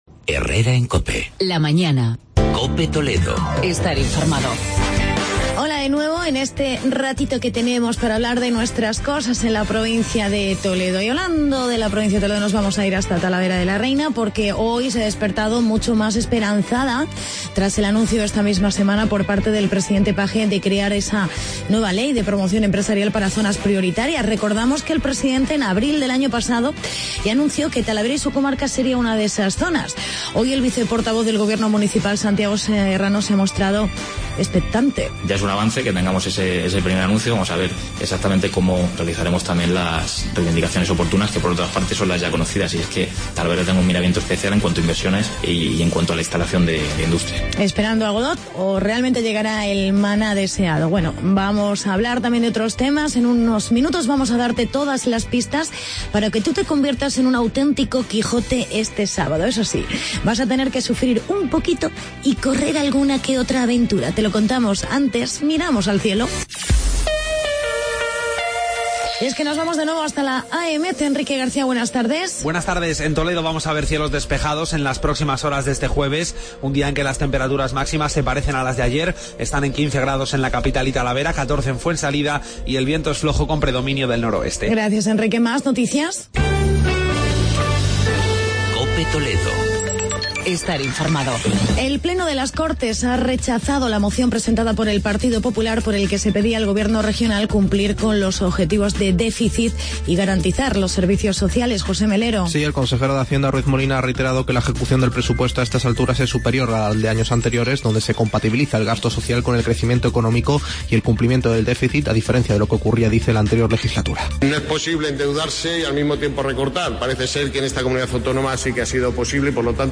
Actualidad y entrevista